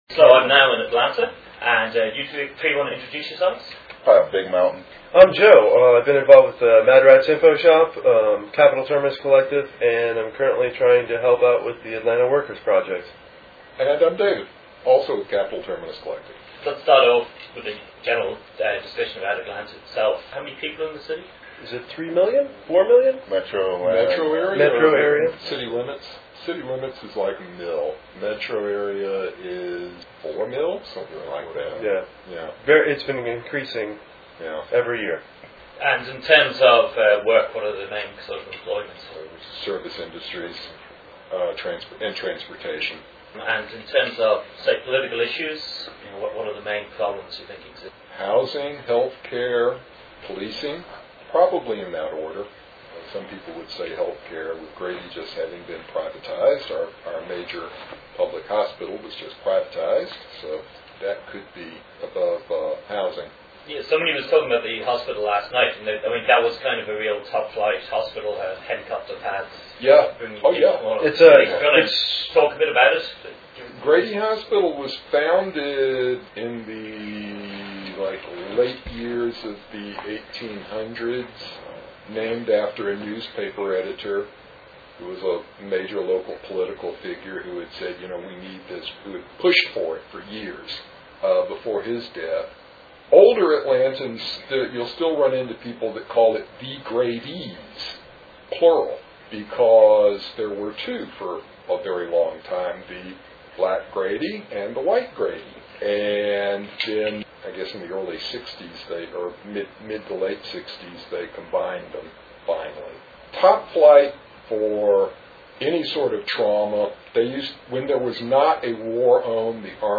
Interview with 3 members of the Capital Terminus Collective in Atlanta, Georgia. Interview covers hospital privitizations, housing, police corruption, segregation of the working class and ends with the usual question about the US election.
Interview with CTC members Interview with CTC members 4.96 Mb